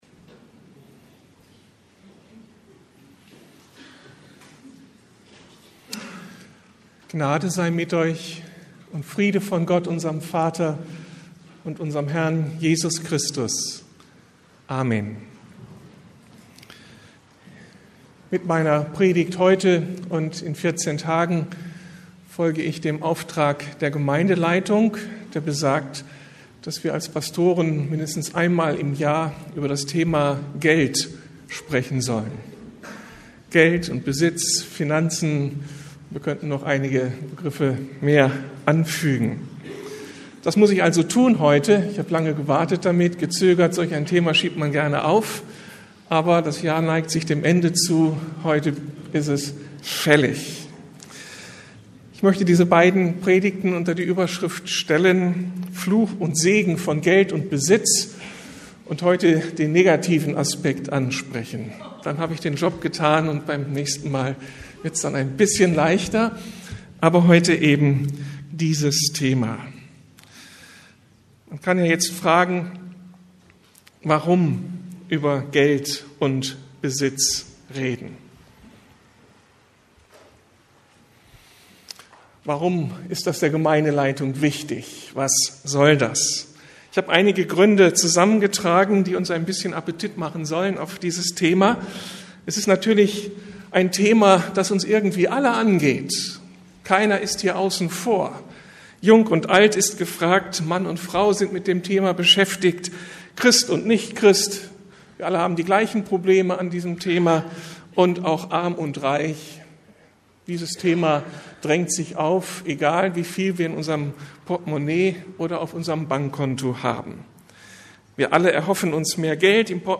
Fluch und Segen von Geld und Besitz, Teil 1 ~ Predigten der LUKAS GEMEINDE Podcast